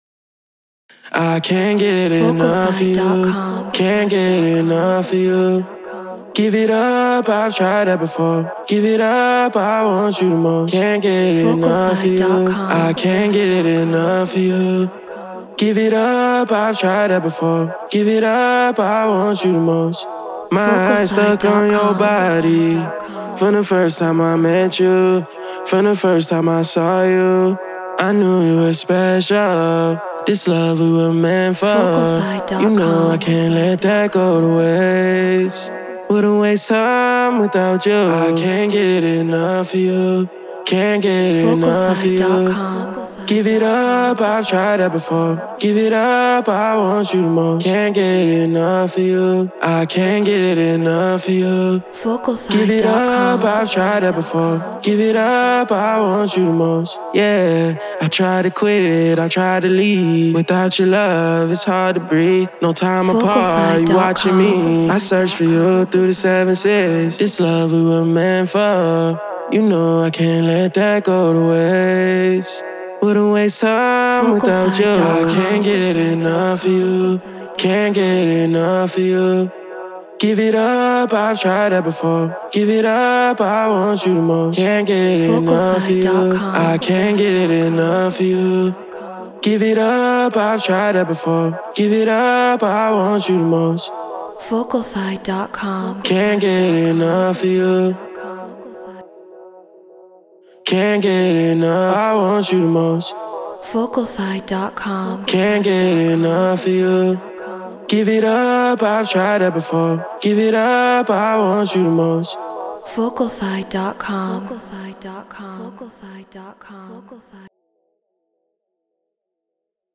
RnB 104 BPM C#min
Human-Made
Studio Mic Treated Room